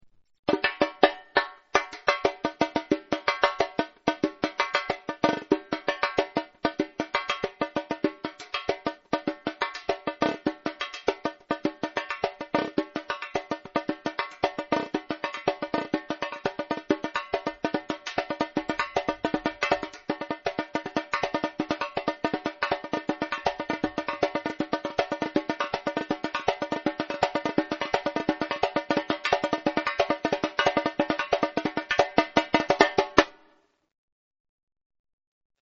Repiniq Pandeiro
REPINIQ.mp3